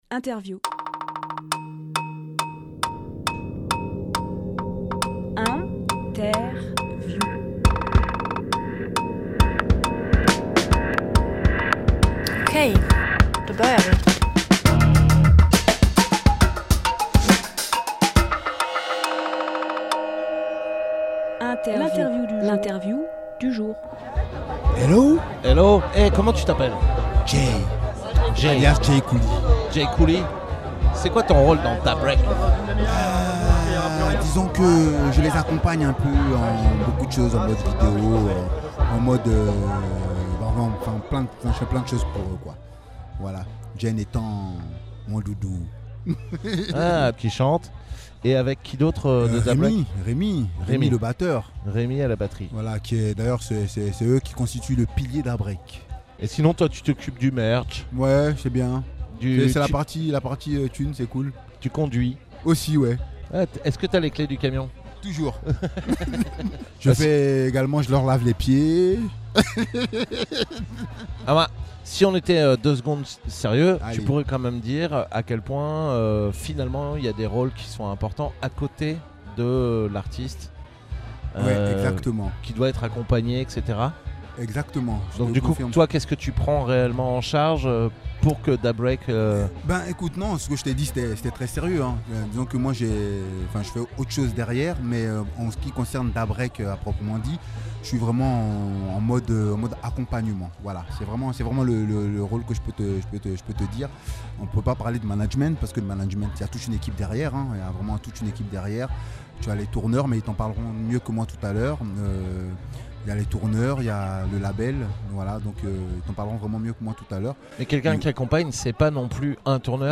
Emission - Interview Aoustock Festival 2022 : Da Break Publié le 13 octobre 2022 Partager sur… Télécharger en MP3 RDWA a installé son studio mobile à Aouste-sur-Sye à l’occasion du Aoustock Festival 2022 qui s’est déroulé du 25 aout au 27 aout. Tout au long de ces trois soirées de concerts nous avons recueillis les paroles des artistes présents.